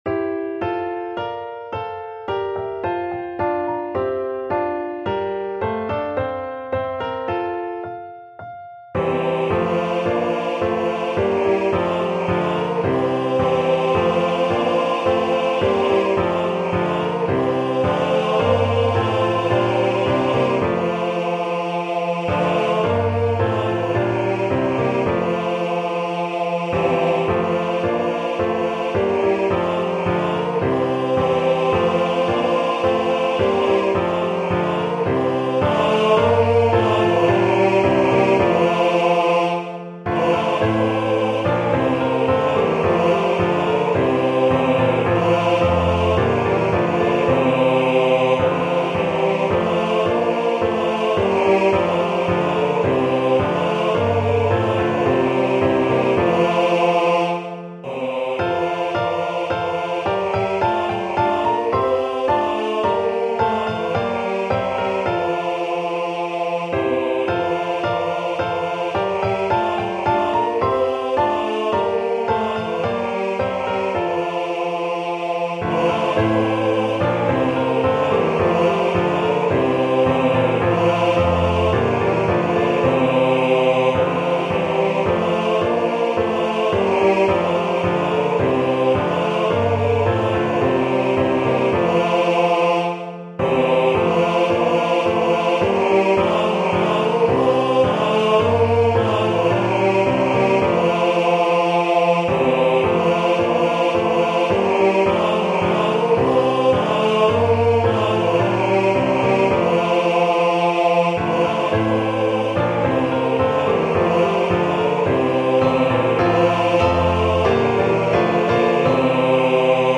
Voicing/Instrumentation: SA , TB , Duet